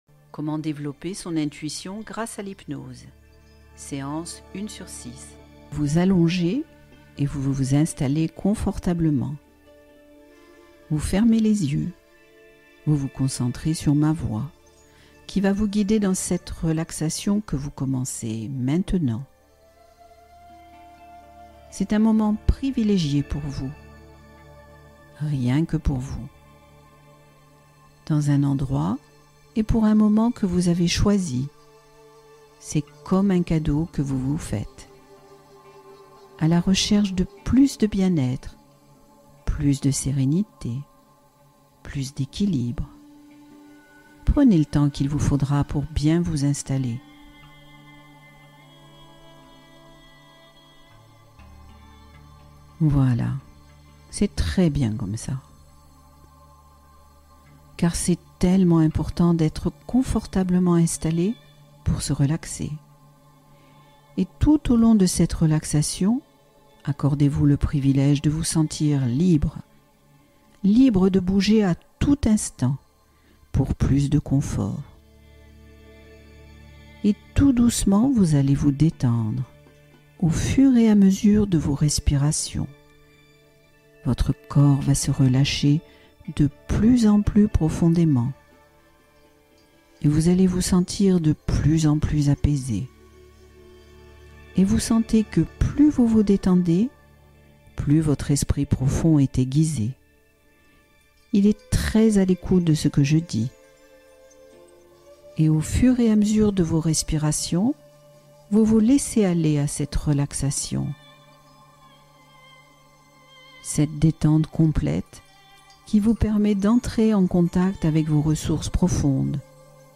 Sommeil apaisant : méditation guidée pour s’orienter vers la détente